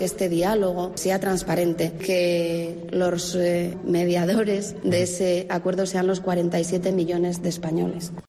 Pues ayer, Lastra fue a desayunar al foro de Nueva Economía. Y habló de la exigencia de Torra del mediador de marras.